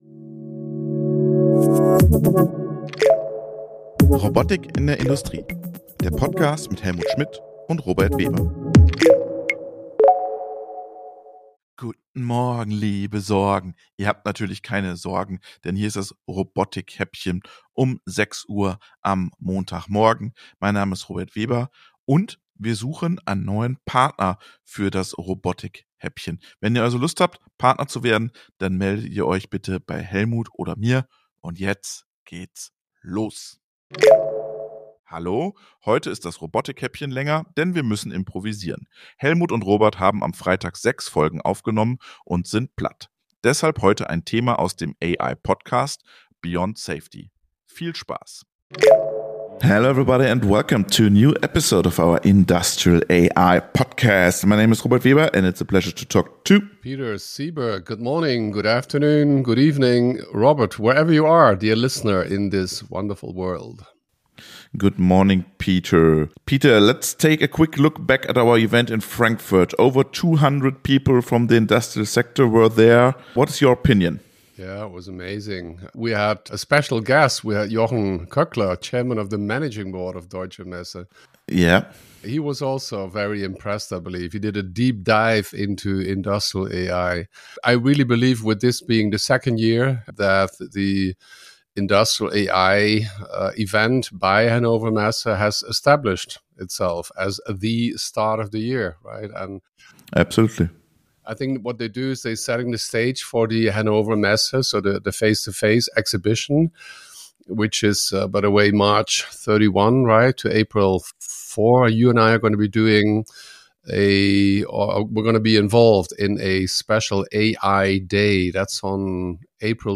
Unser Newshäppchen für die Fahrt zur Arbeit. Immer Montags gibt es die Robotik News - mit-recherchiert, geschrieben und gesprochen mit und von einer KI.